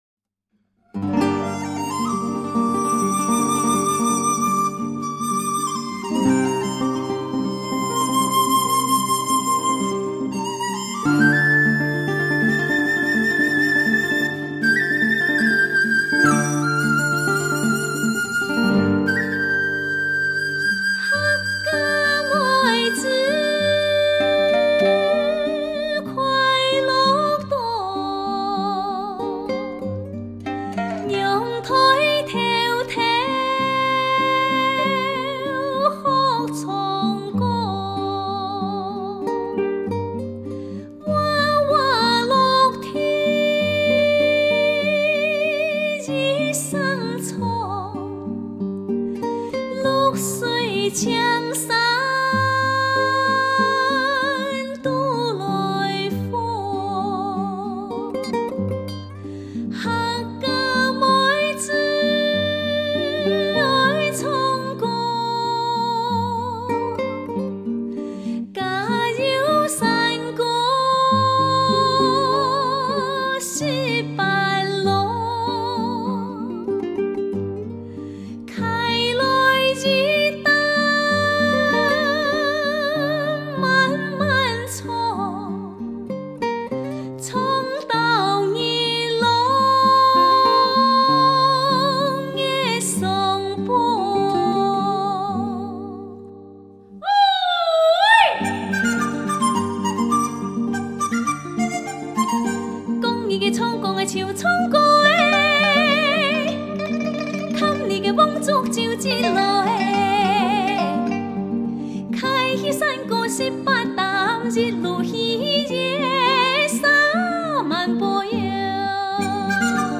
原汁原味 乡土气息浓郁地道
她演唱的客家山歌不但语言原汁原味、乡土气息浓郁地道，而且能将自己的歌唱技巧融汇到这些民歌中去，使歌声变得更为纯粹、美妙。